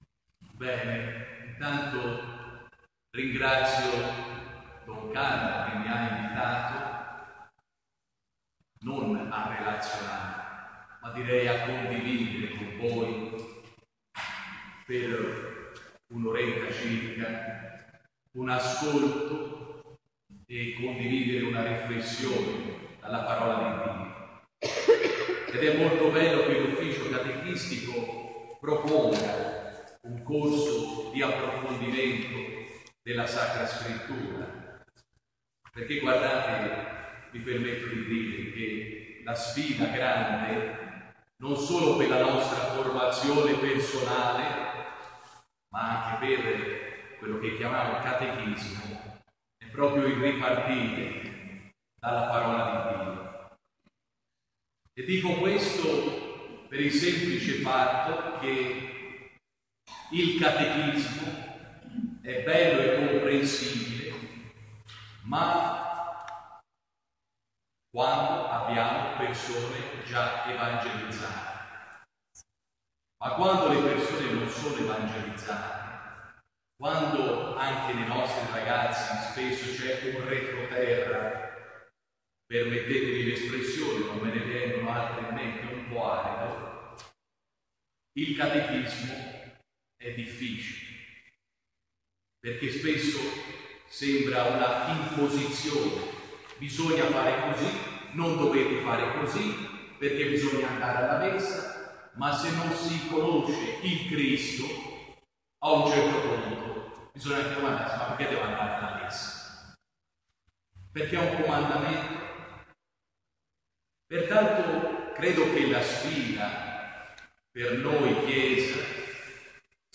prima-lezione-corso-vangelo-matteo.mp3